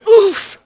UUMPH.WAV